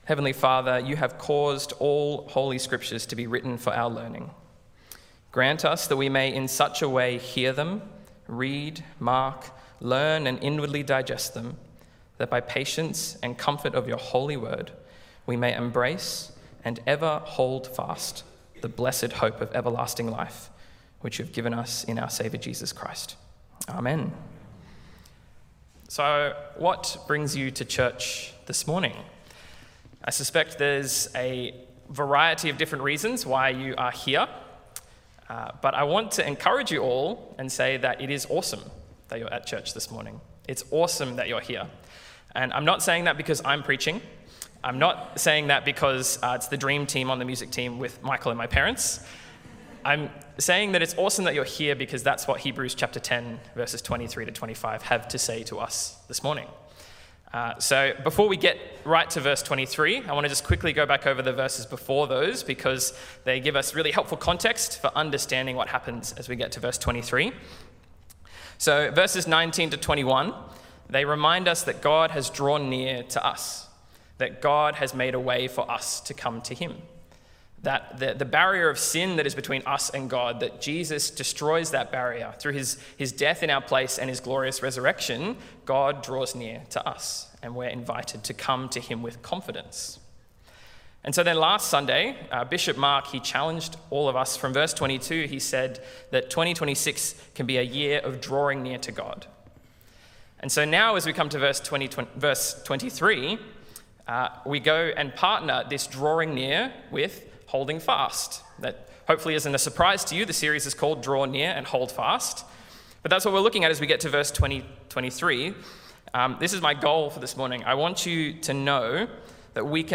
Sermon on Hebrews 10:23-25 - Hold Fast